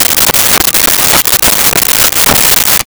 Saw Wood 05
Saw Wood 05.wav